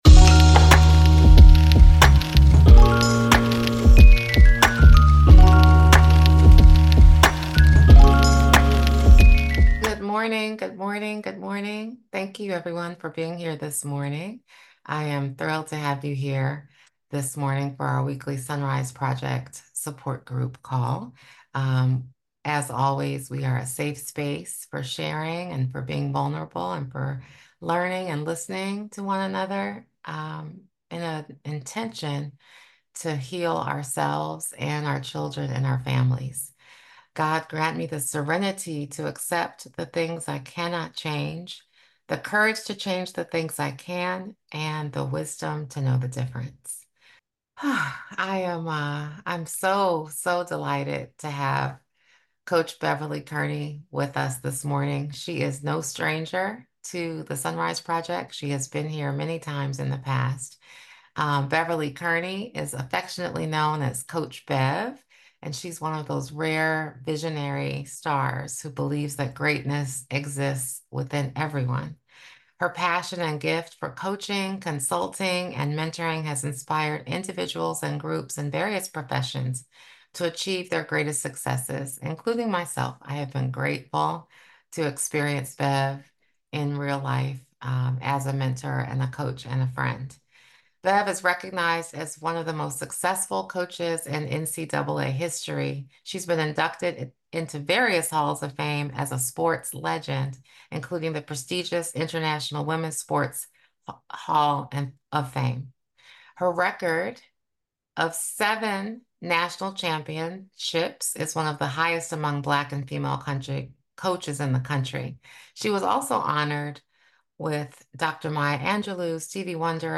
engages in an open and candid conversation about overcoming challenges, building resilience, and stepping into your power.